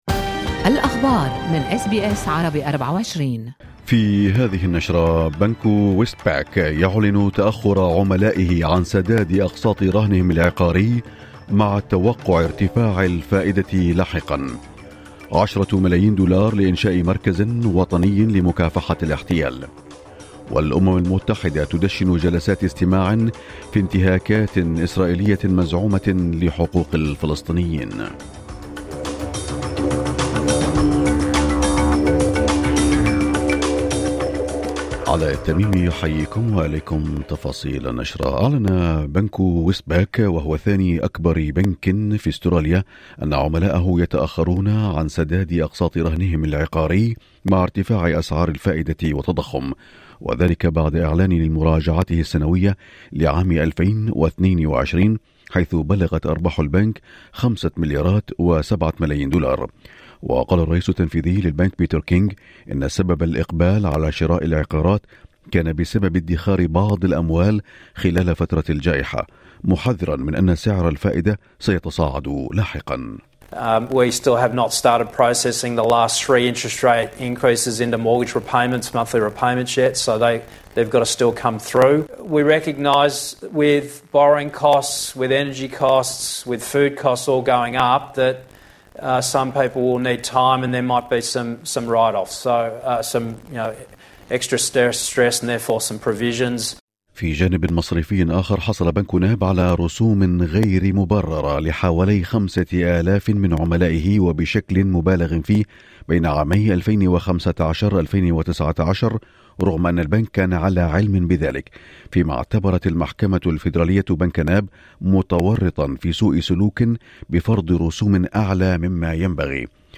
نشرة أخبار الصباح 8/11/2022